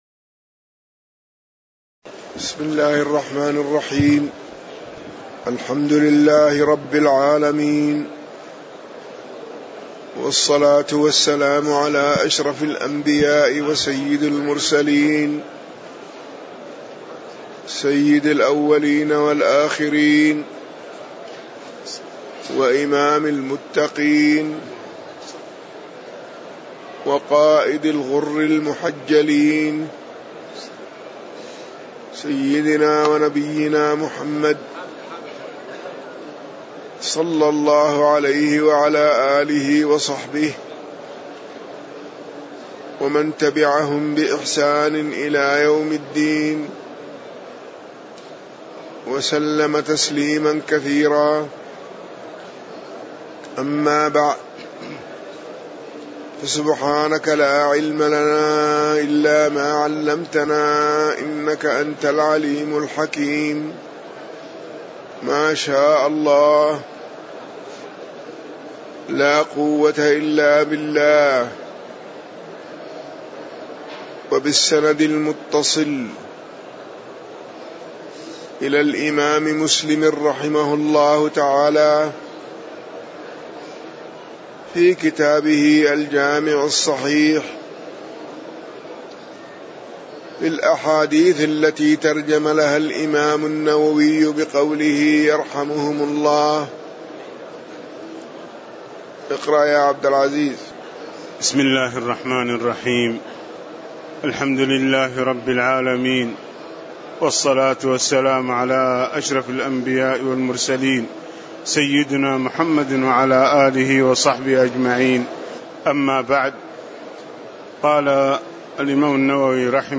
تاريخ النشر ١٢ شعبان ١٤٣٨ هـ المكان: المسجد النبوي الشيخ